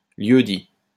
Plik audio z wymową.